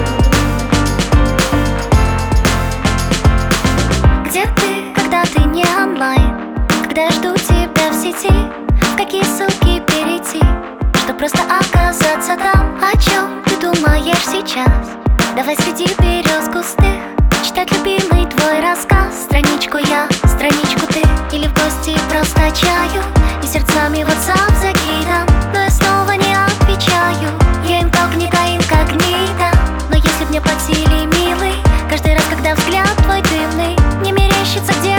Жанр: Поп музыка / Русский поп / Русские
Teen Pop, Pop